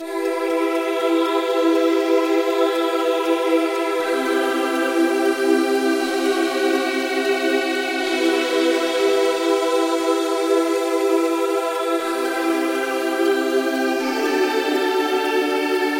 Fm Eb Eb Db中的垫子
描述：这是在Samplitude的Cinematic Synth虚拟乐器上创建的一个空气垫。 和弦是F小调、E大调、Eb/G和Db。 它可以很好地用于冰冷的节拍、环境、音景或电影轨道。 120bpm。
标签： 120 bpm Chill Out Loops Pad Loops 2.69 MB wav Key : Fm Samplitude
声道立体声